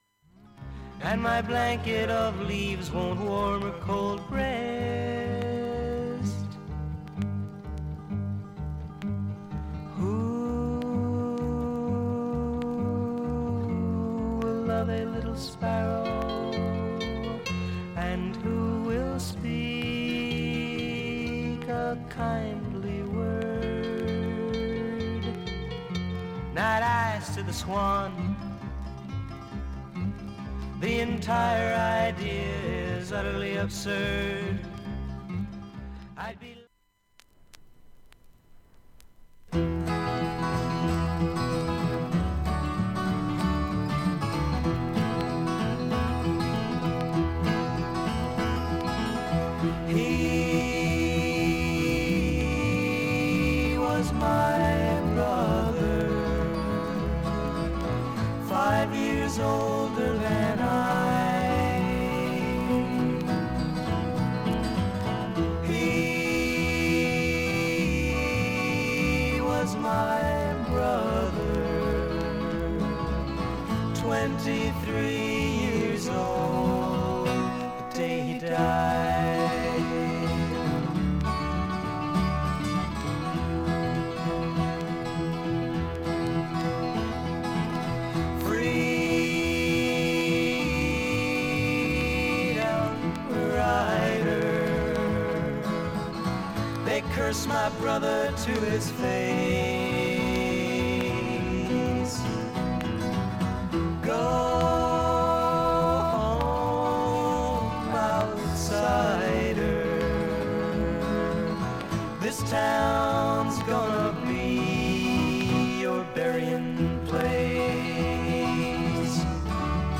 普通にいい音質です全曲試聴済み。
ほか6回のかすかなプツが２箇所
単発のかすかなプツが６箇所
◆ＵＳＡ盤 2nd stereo pressing